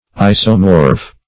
Search Result for " isomorph" : The Collaborative International Dictionary of English v.0.48: Isomorph \I"so*morph\, n. [See Isomorphous .] 1. A substance which is similar to another in crystalline form and composition.